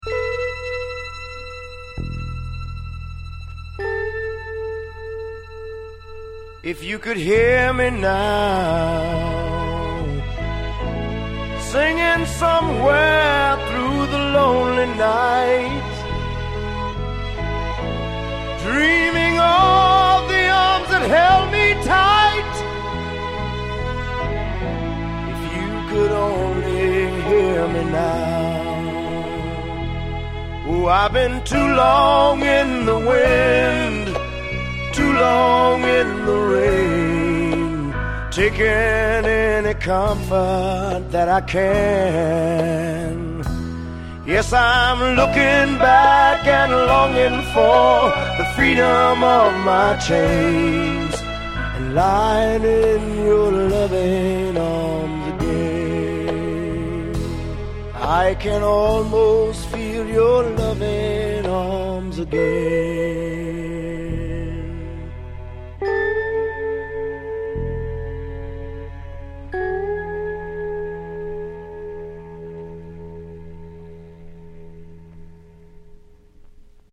Genre: Deep Soul